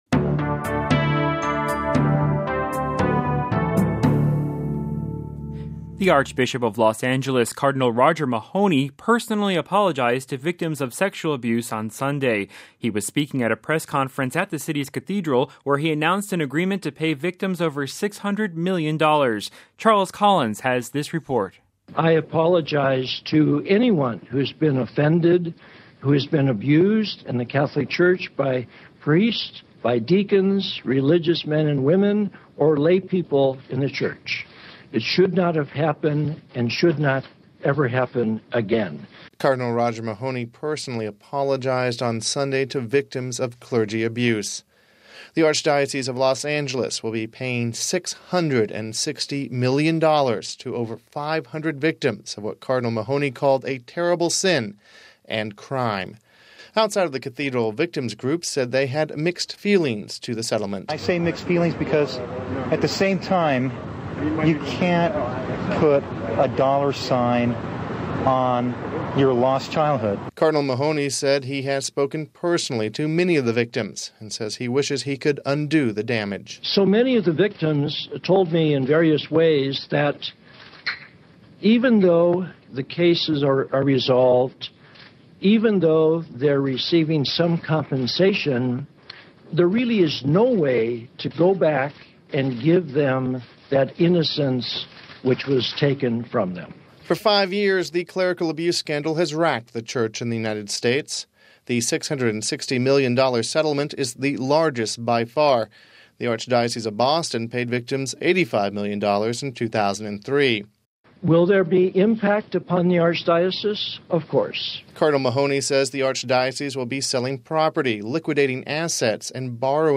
(16 July 2007 - RV) The Archbishop of Los Angeles, California, Cardinal Roger Mahony, personally apologized to victims of sexual abuse on Sunday. He was speaking at a press conference at the city's cathedral, where he announced an agreement to pay victims over 600 million dollars.